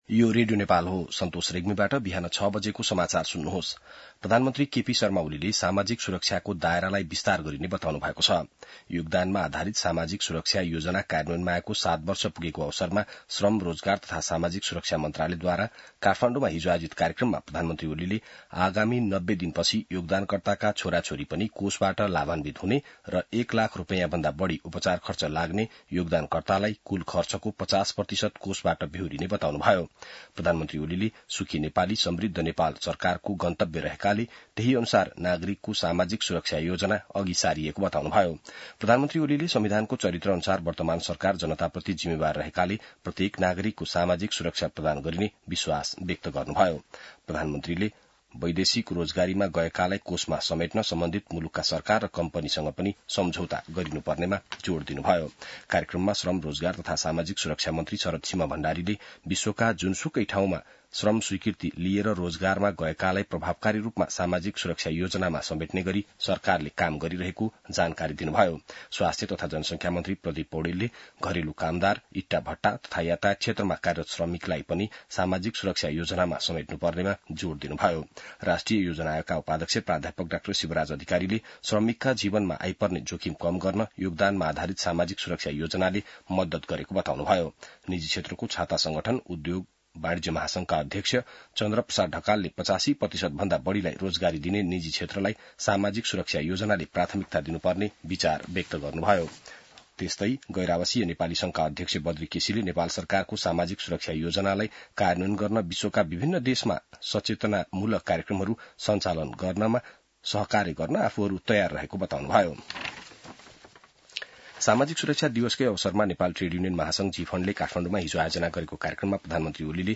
An online outlet of Nepal's national radio broadcaster
बिहान ६ बजेको नेपाली समाचार : १३ मंसिर , २०८१